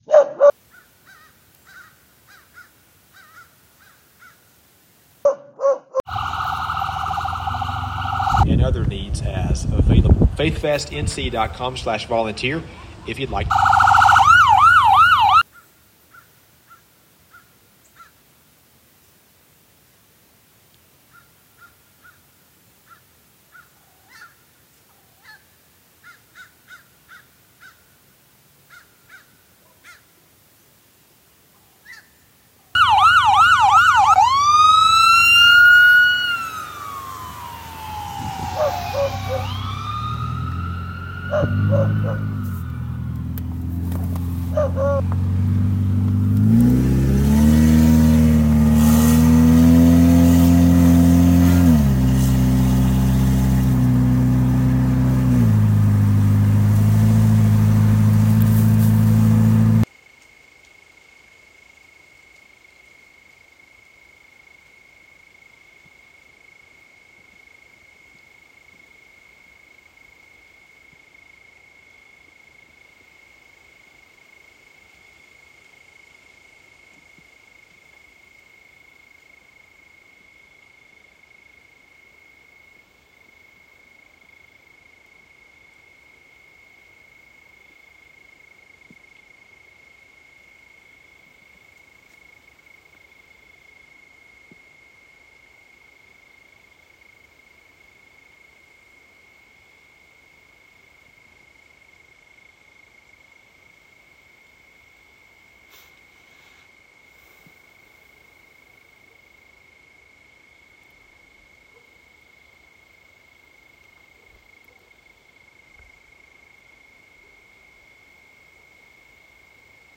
It’s experimental.
It's a headset thing. It ranges from field recordings to noise to 4-track dual mono sounds to whatever comes to mind.